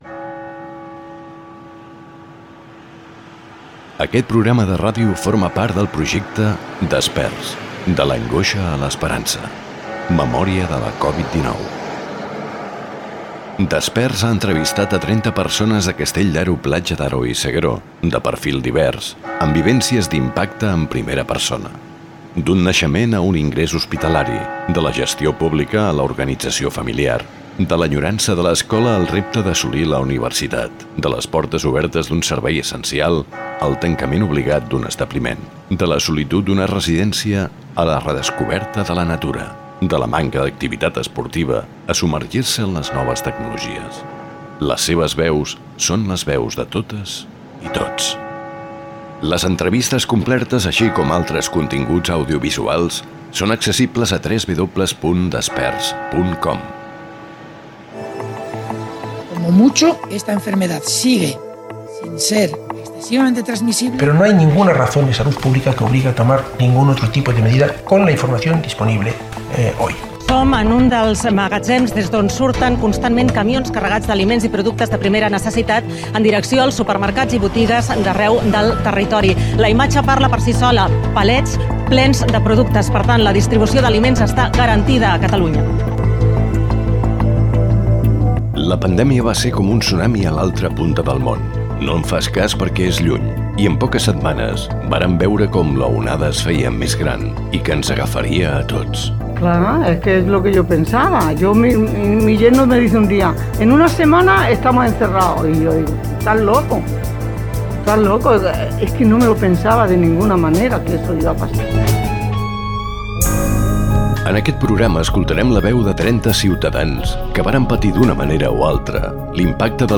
Careta del programa i presentació amb l'objectiu del projecte. Record de com va començar la pandèmia de la COVID-19 que va arribar a Catalunya a començament de l'any 2020. Recull d'opinions i records de diverses persones.
Informatiu
Fragment extret de l'arxiu sonor de Ràdio Platja d'Aro.